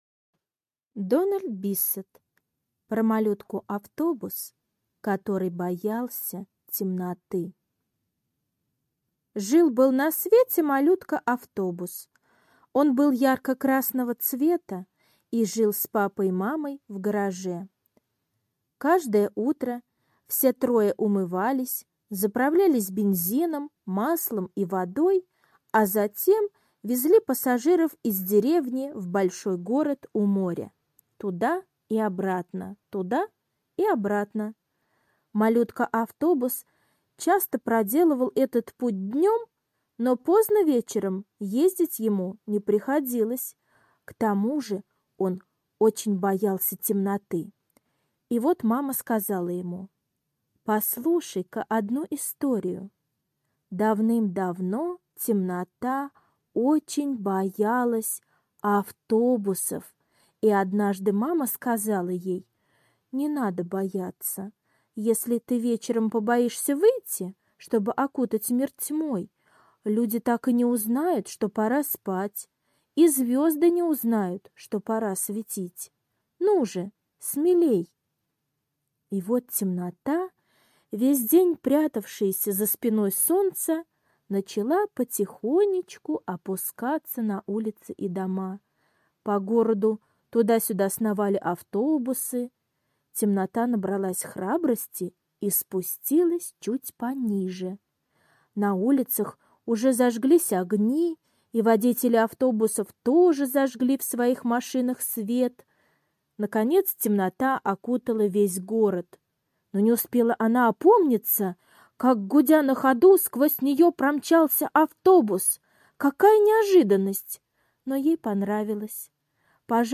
Про малютку-автобус, который боялся темноты - аудиосказка Биссета Д. Как мама-автобус научила своего малютку-автобуса не бояться темноты.